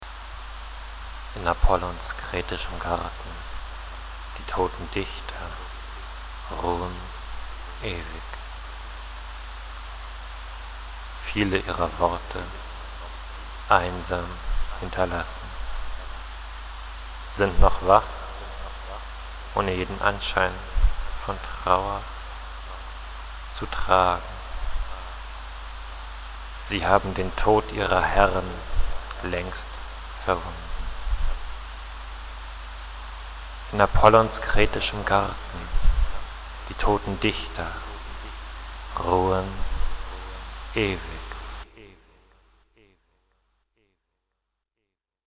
Hier gibt es einige improvisierte Lesungen meiner Texte zum Herunterladen im MP3-Format, in seltenen Fällen sind sie musikalisch untermalt. Es handelt sich um Lo-Fi Produktionen, wenn man sie überhaupt Produktionen nennen mag.